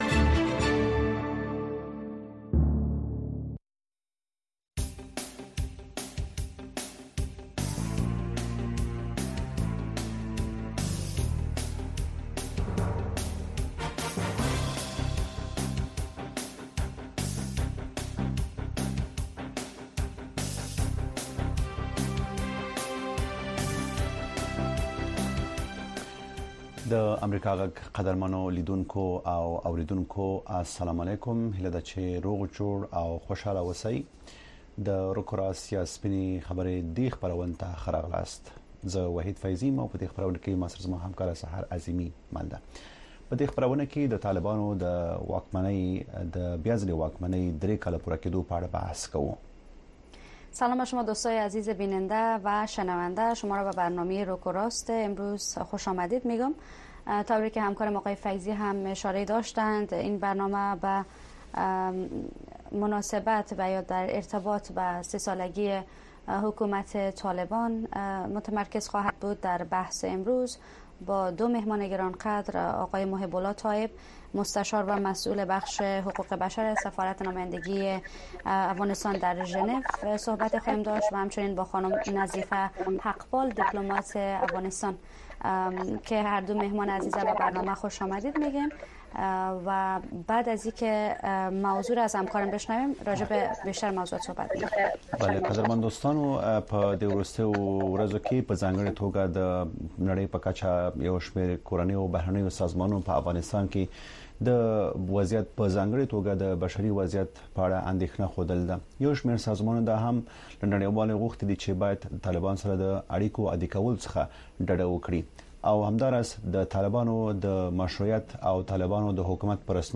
در برنامۀ رک و راست بحث‌های داغ صاحب‌نظران و تحلیلگران را در مورد رویدادهای داغ روز در افغانستان دنبال کرده می‌توانید. این برنامه زنده به گونۀ مشترک به زبان‌های دری و پشتو هر شب از ساعت هشت تا نه شب به وقت افغانستان پخش می‌شود.